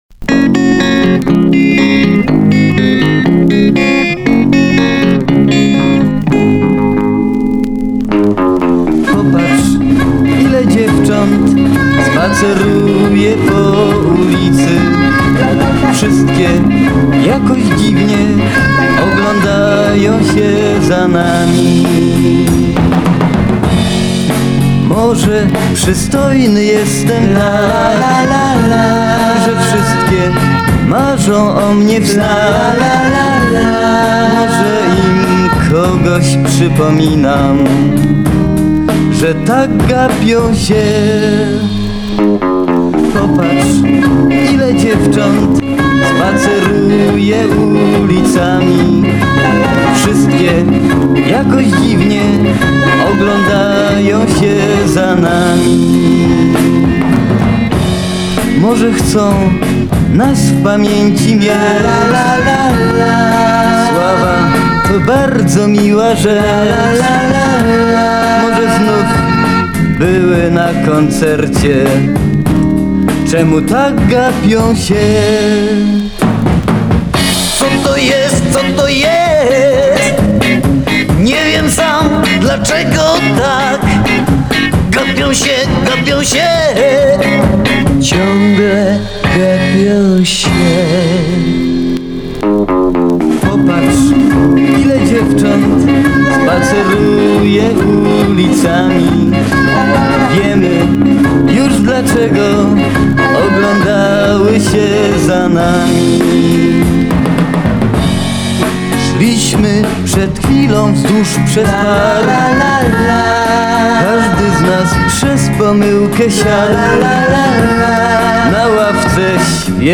Пионеры польского биг-битa.